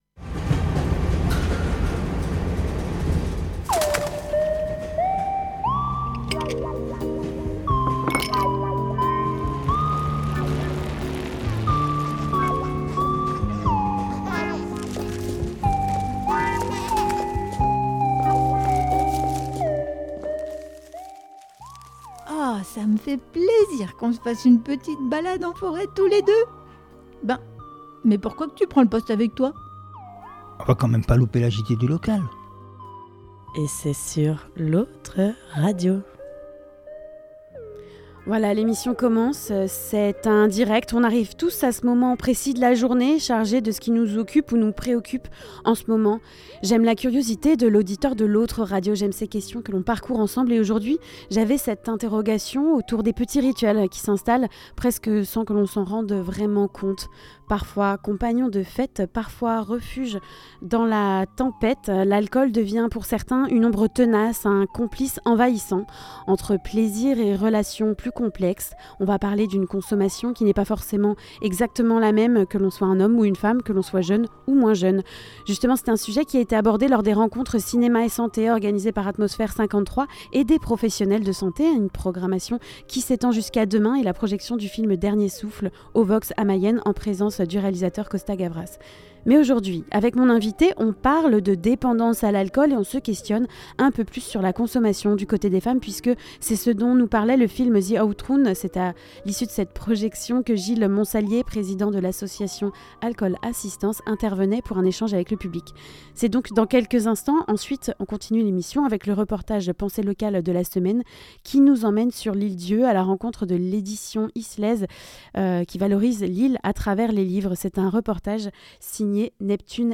Le reportage Penser Local : Édition Islaise valorise l’Ile d’Yeu par des livres